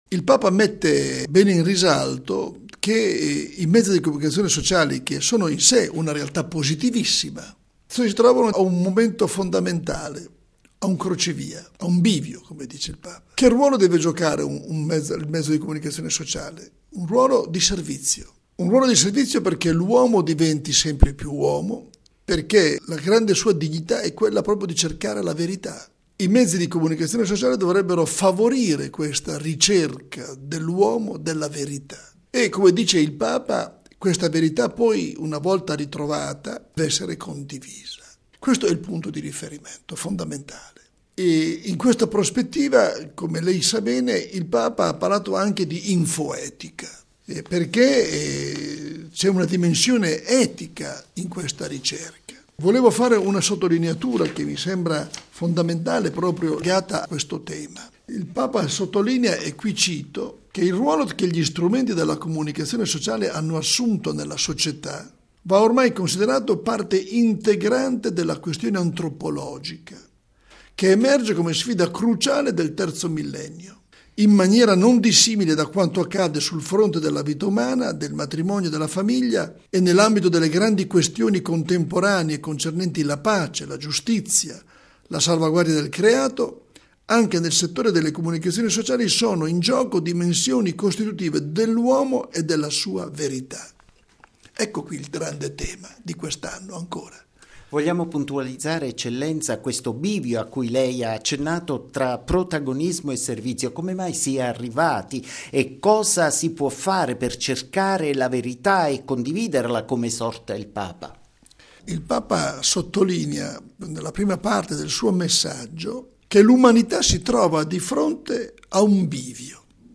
Giornata mondiale delle comunicazioni sociali: il Papa invita i mass media al servizio evitando il protagonismo. Intervista con mons. Celli